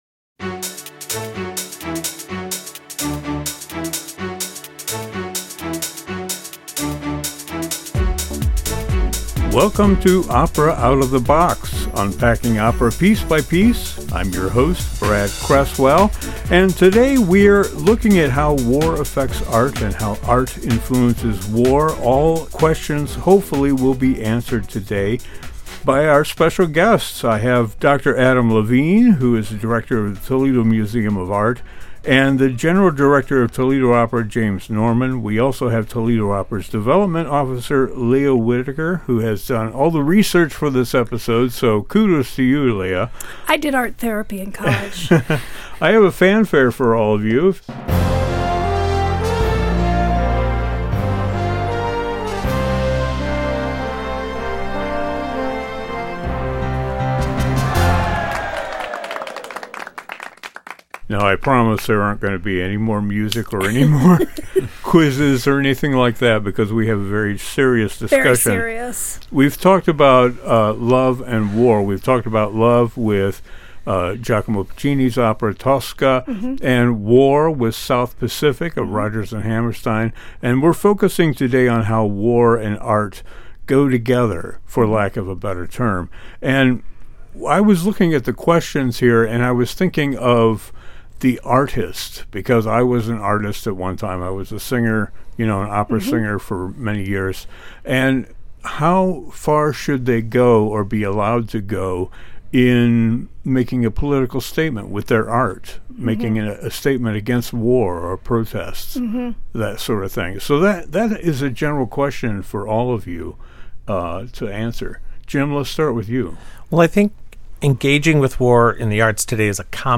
We have a thoughtful and detailed discussion of how war affects art, and vice-versa.